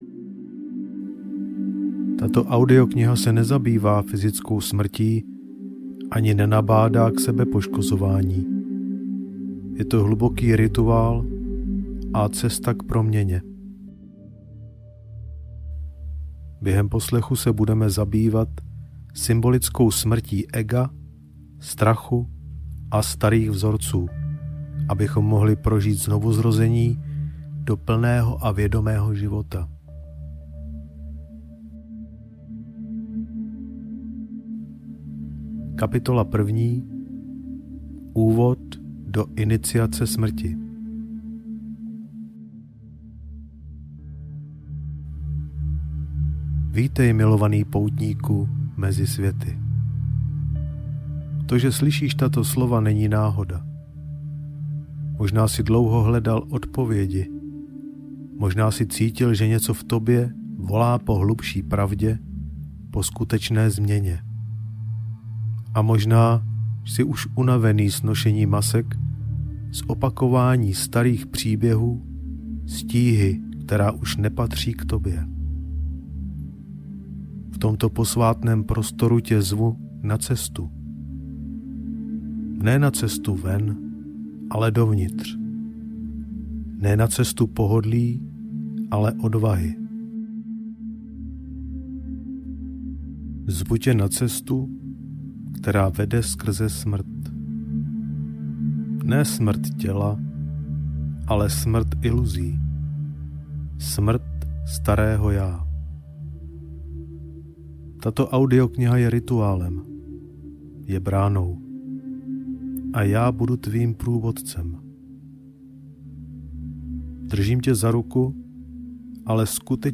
Iniciace smrti – Cesta proměny audiokniha
Ukázka z knihy
iniciace-smrti-cesta-promeny-audiokniha